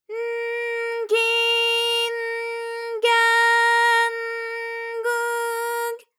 ALYS-DB-001-JPN - First Japanese UTAU vocal library of ALYS.
g_n_gi_n_ga_n_gu_g.wav